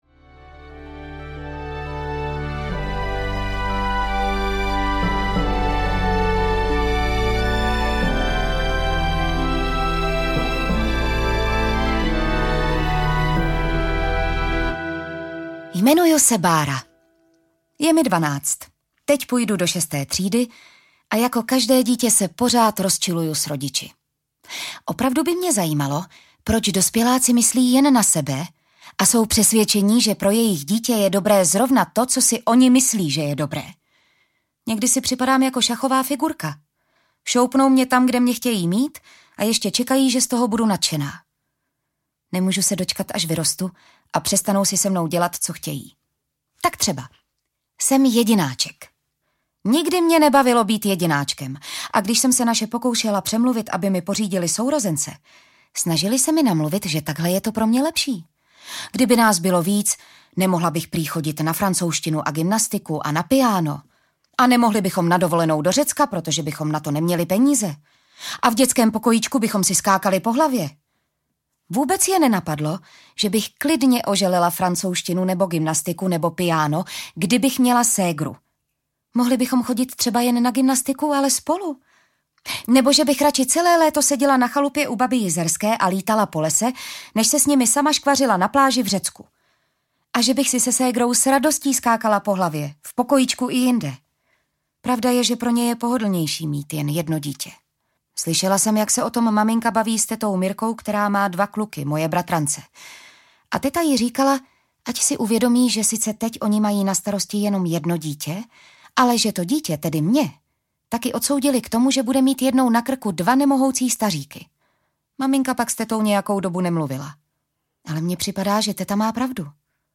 Egypt – V nitru pyramidy audiokniha
Ukázka z knihy
• InterpretJitka Ježková